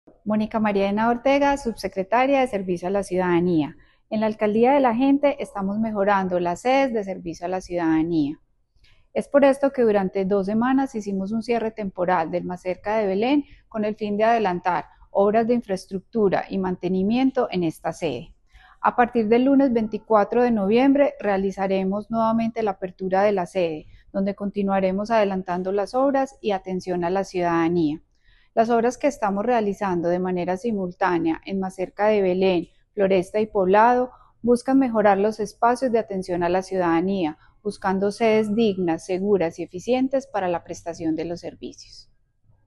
Declaraciones de la subsecretaria de Servicio a la Ciudadanía, Mónica Henao